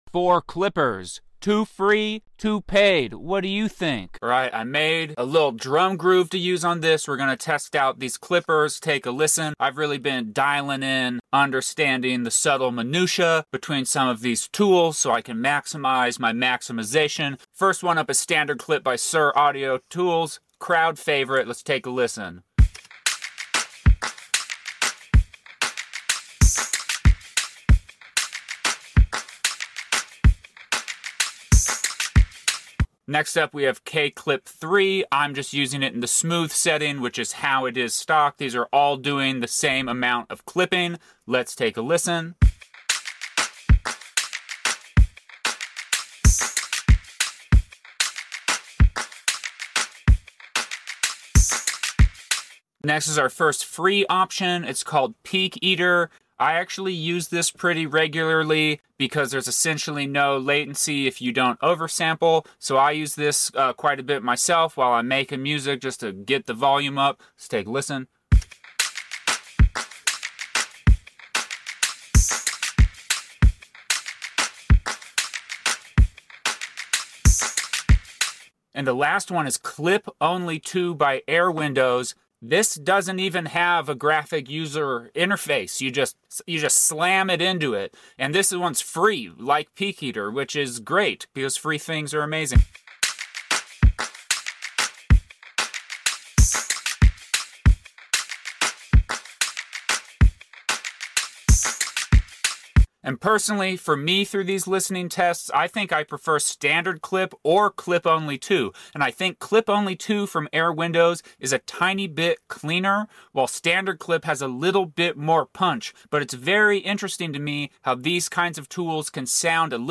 I’ve got my four favorite that also happen to be 2 paid and 2 free plugins. Clipping is distinctly different from limiting as it just chops the top of the waveforms off, but every clipper has a slightly different way of managing that thus it sounds different.